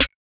Snare SwaggedOut 1.wav